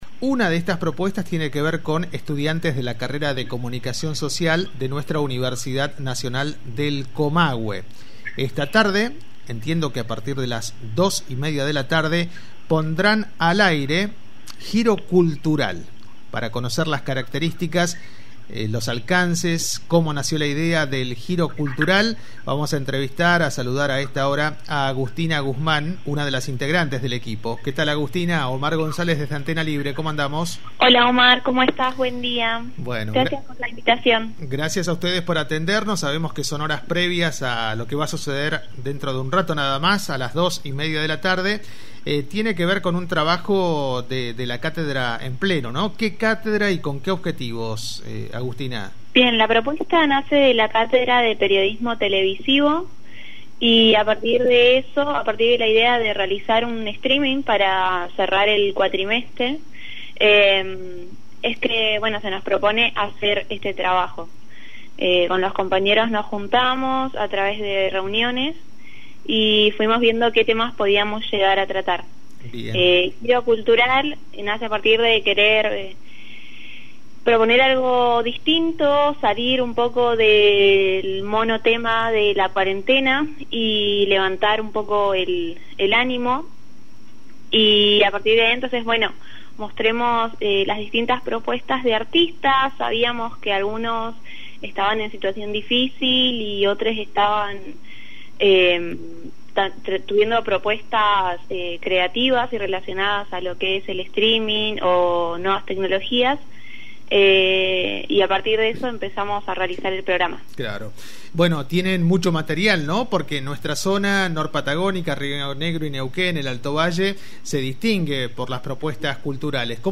integrante del equipo Giro Cultural en comunicación con “Chocolate por la noticia”